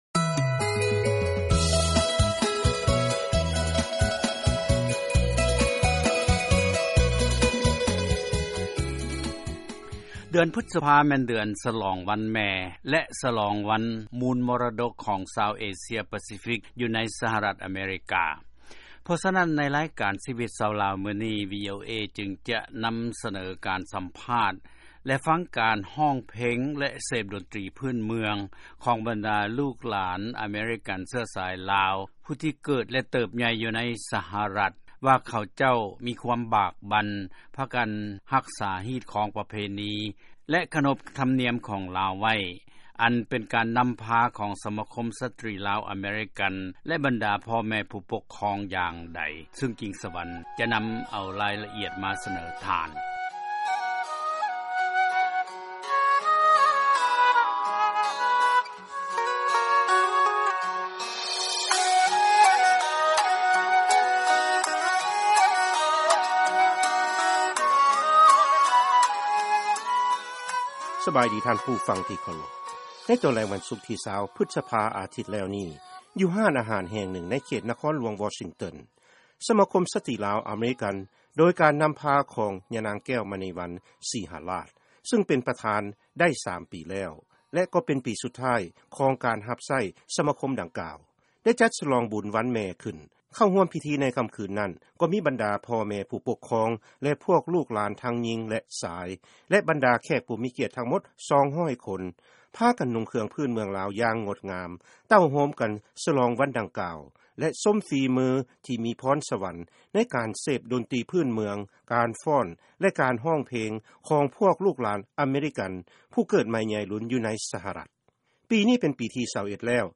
ເຊີນຟັງການສະຫລອງວັນແມ່ ຂອງສະມາຄົມສະຕີລາວອາເມຣິກັນ ທີ່ນະຄອນຫລວງ ວໍຊິງຕັນ